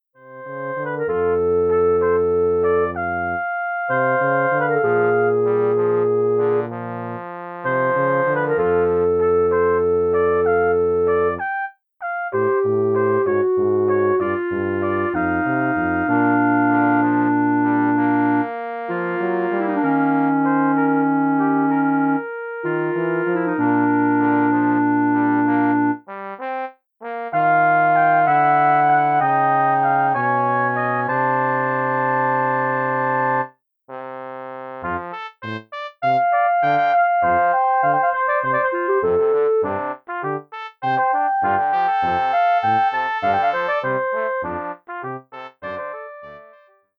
wind quartet